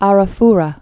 A·ra·fu·ra Sea
rə-frə)